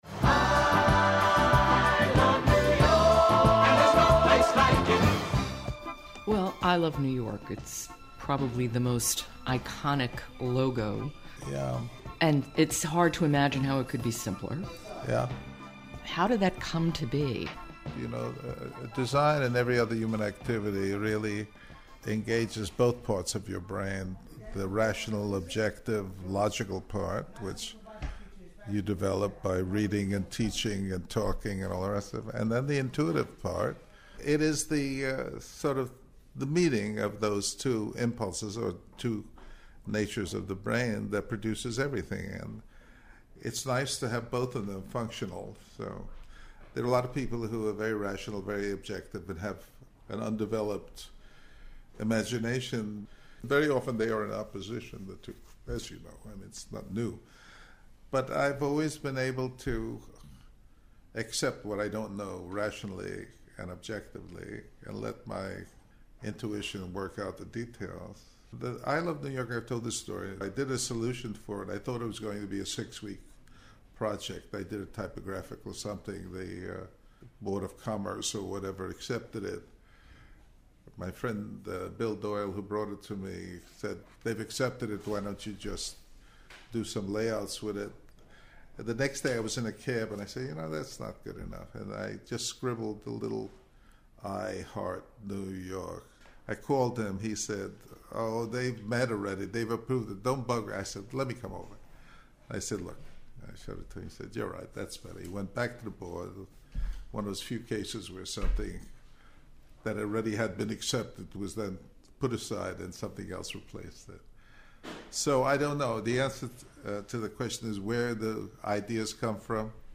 Transcript of conversation with Milton Glaser